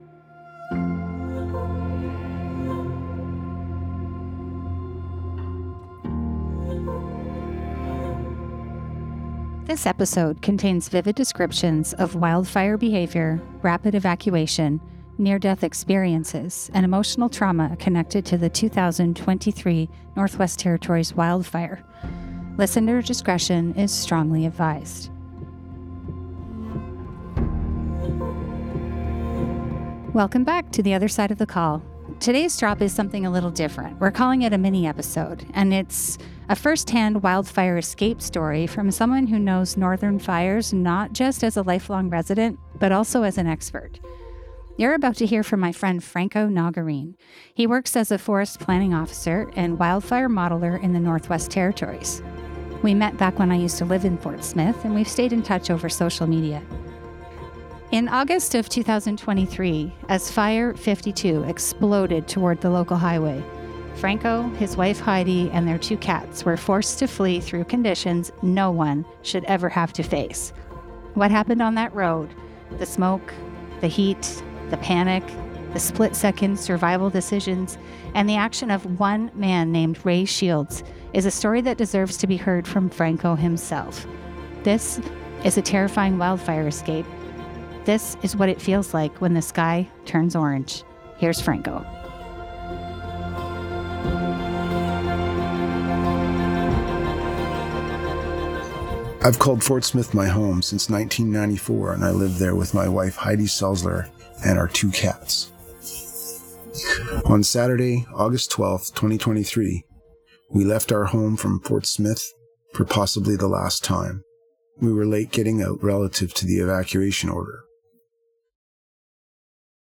reads his unedited